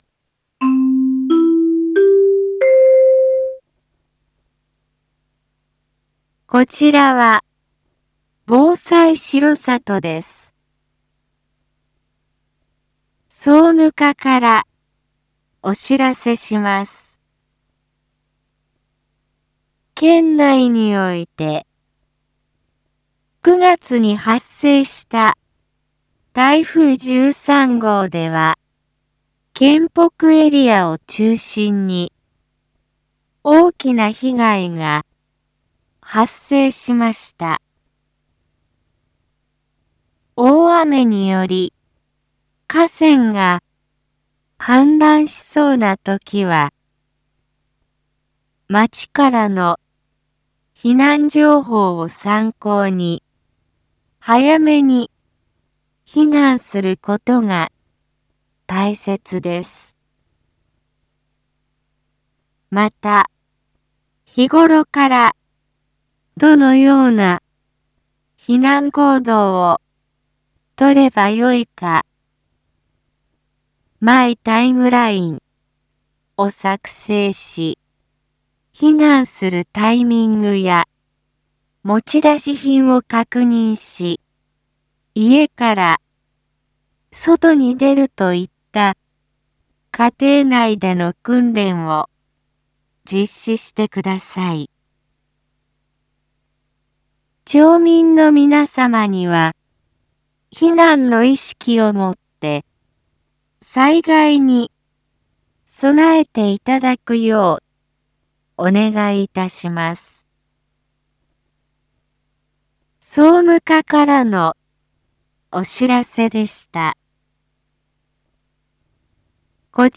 Back Home 一般放送情報 音声放送 再生 一般放送情報 登録日時：2023-10-14 19:02:12 タイトル：★災害時の避難行動のお願い★ インフォメーション：こちらは、防災しろさとです。